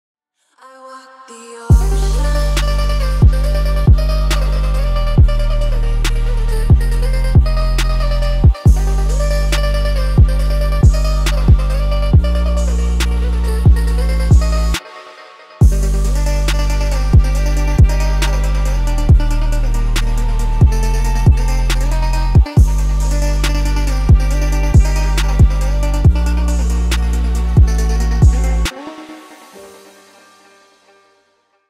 • Качество: 320, Stereo
атмосферные
Trap
восточные
качающие
загадочные
Оригинальный атмосферный трэп.